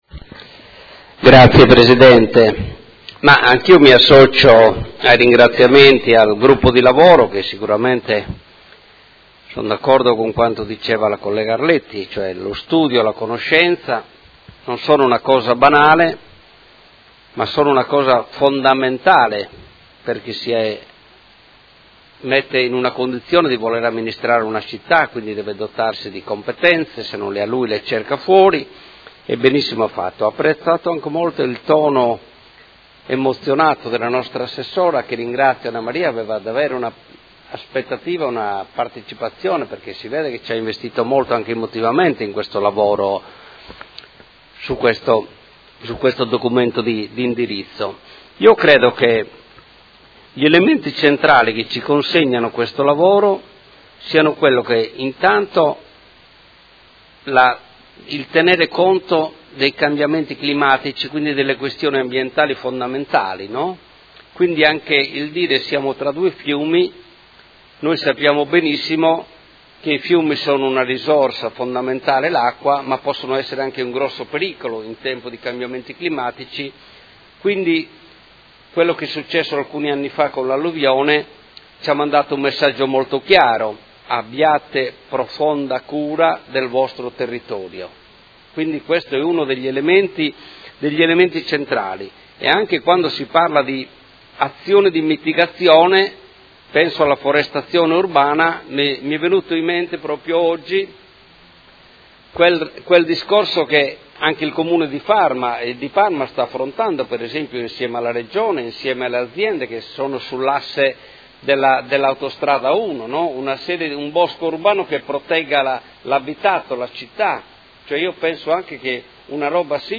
Seduta del 21/02/2019. Dibattito su proposta di deliberazione: Delibera di indirizzo, ai sensi dell'articolo 4 comma 2 Legge Regionale 21 dicembre 2017 n. 24 Disciplina regionale sulla tutela e l'uso del territorio - Approvazione Allegato A, Relazione, Volume; e Ordine del Giorno